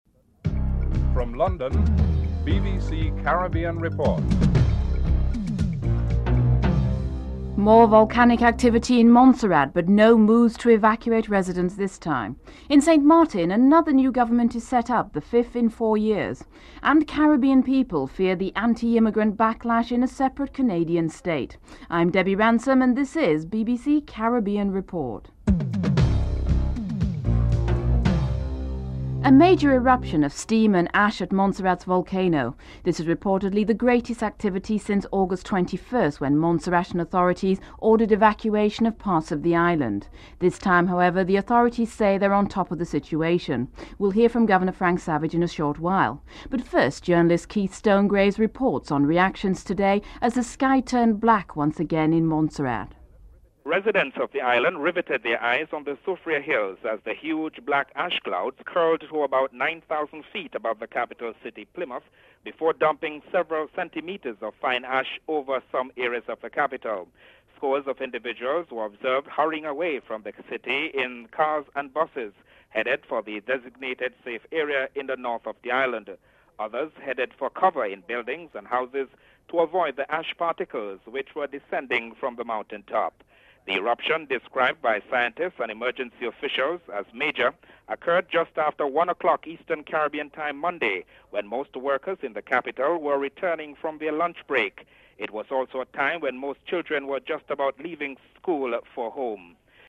The people of Antigua have their say on this third political party.
dc.formatStereo 192 bit rate MP3;44,100 Mega bits;16 biten_US
dc.typeRecording, oralen_US